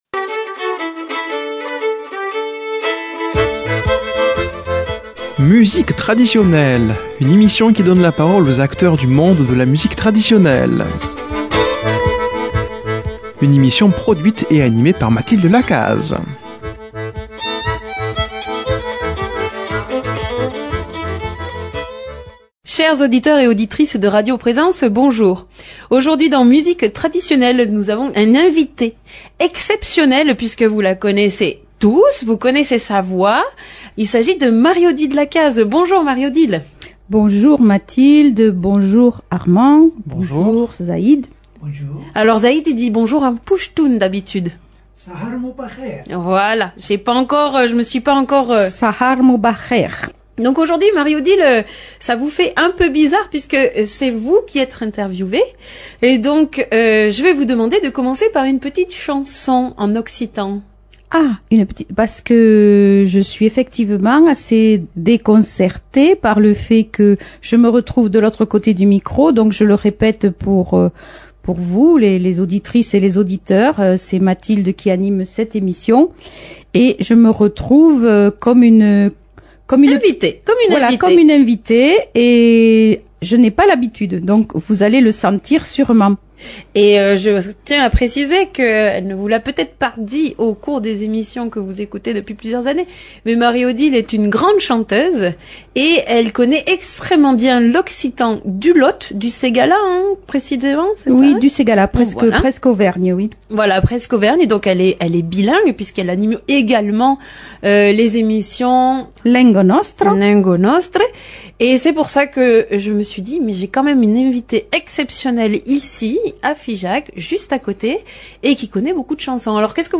Aujourd'hui dans Musique Traditionnelle